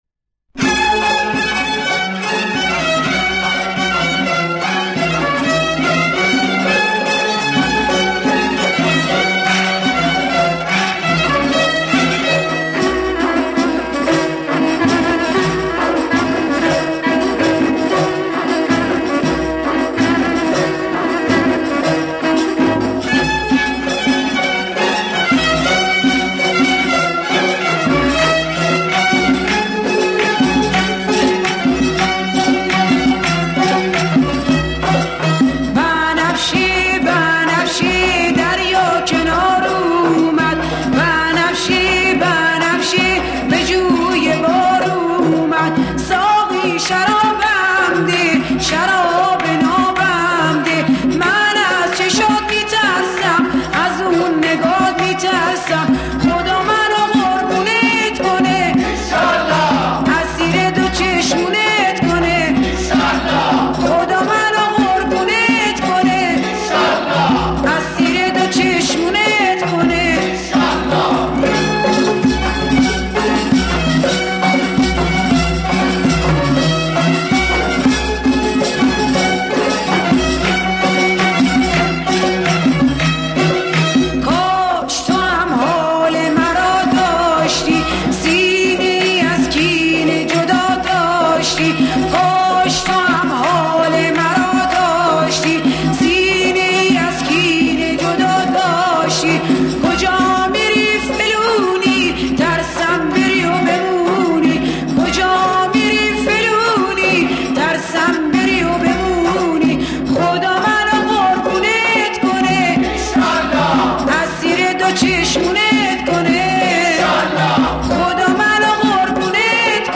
با صدای زن 2